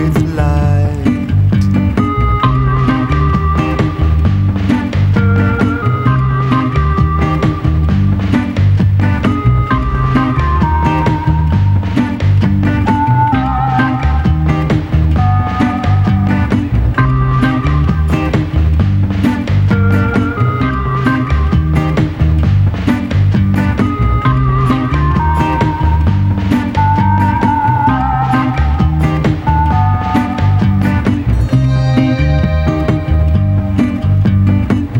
Жанр: Поп / Инди
# Indie Pop